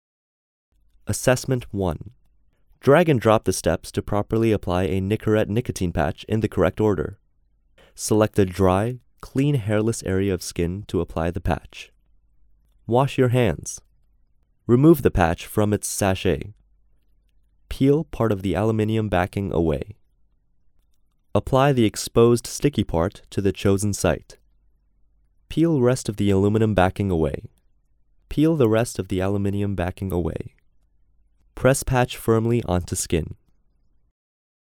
Narration audio (WAV)